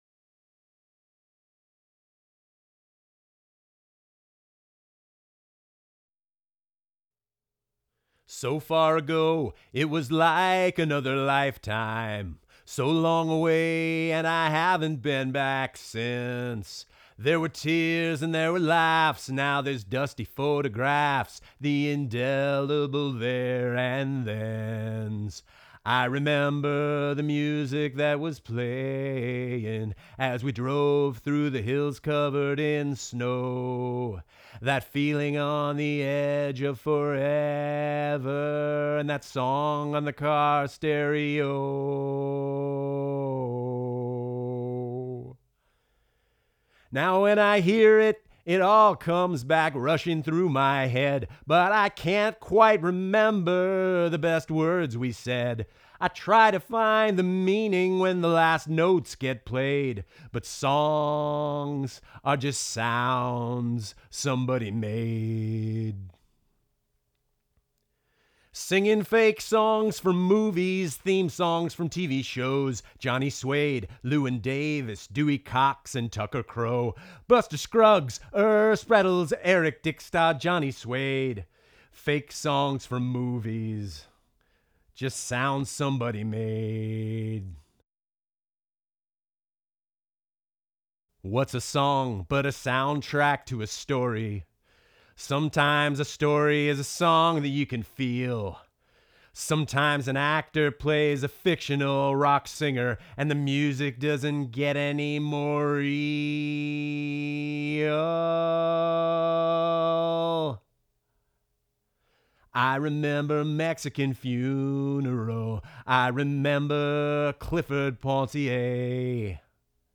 leadvocal.wav